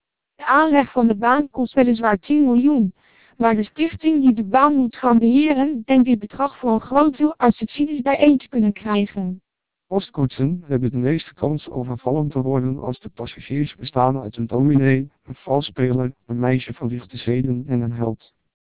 Speech Samples (WAV-files).
twelp600_du_short.wav